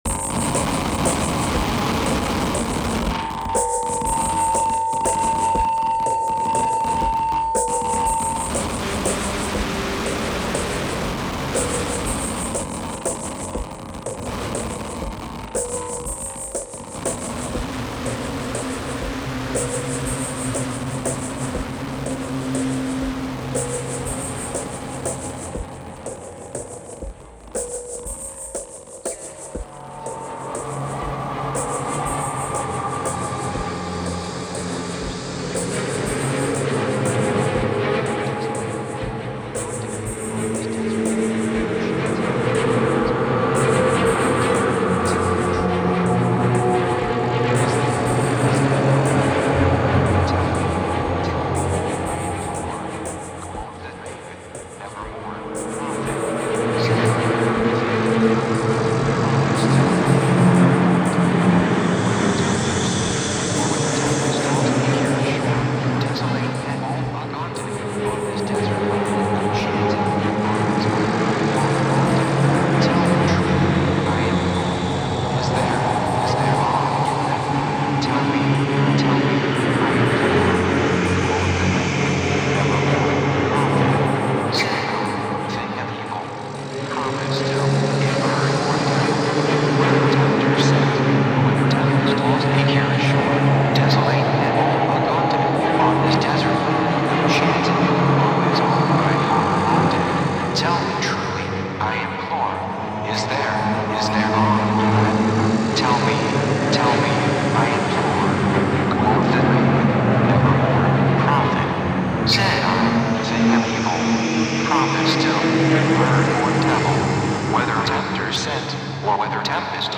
本作は大編成のストリングスと男性合唱からなるサイバー・ゴシックなオーケストレーションと、
デジタル・ハードコアなビートとのアマルガムである。
ヒトに非ざる非実存ナレーターは、MacOS X Leopardによる合成言語。
漆黒のサイバー・ハードコア・ゴシックが鳴る。